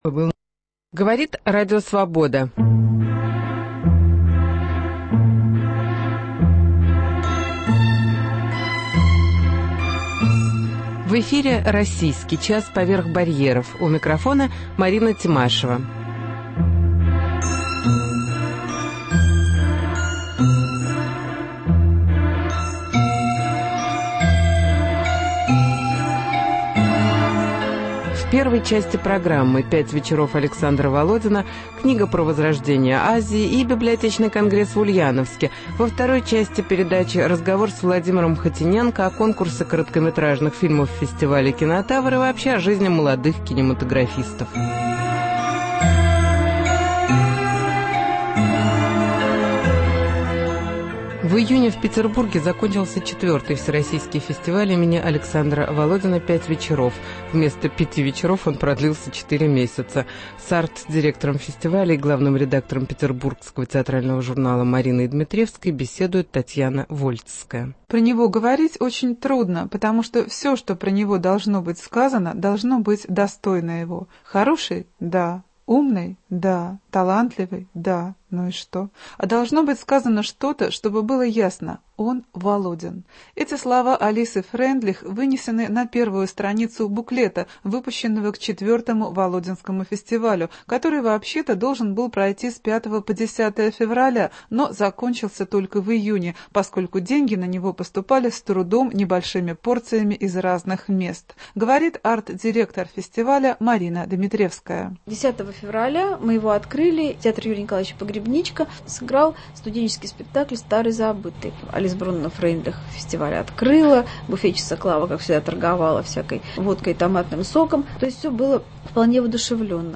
Всероссийский библиотечный конгресс в Ульяновске. Интервью с кинорежиссером Владимиром Хотиненко